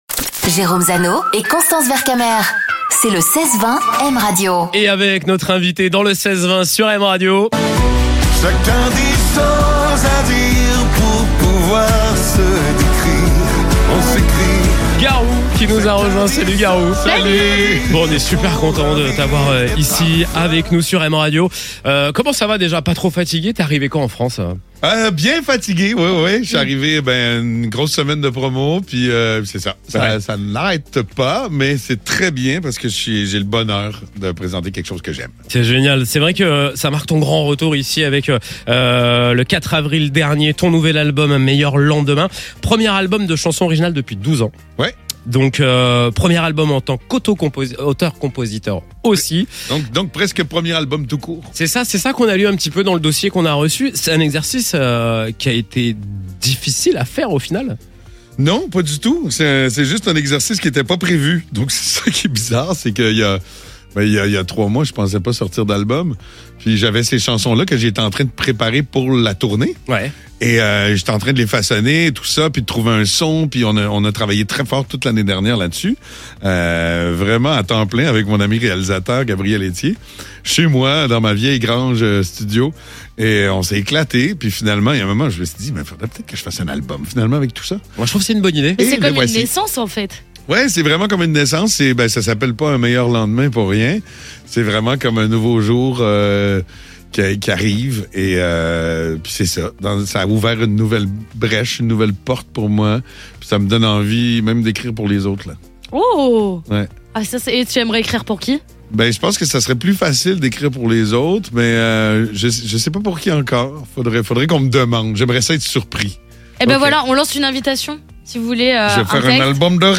Garou était l'invité du 16 / 20 sur M Radio